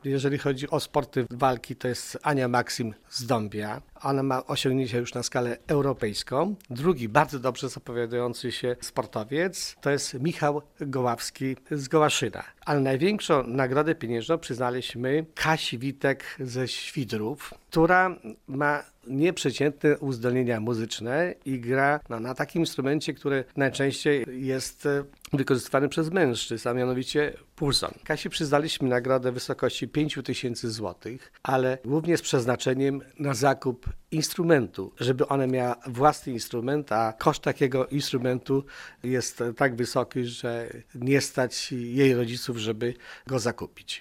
Właśnie przyznano pierwsze takie wyróżnienia dla wybitnych sportowców i muzyków, którzy na swoim koncie już mają poważne sukcesy na arenie krajowej i międzynarodowej – informuje zastępca wójta Gminy Łuków Wiktor Osik: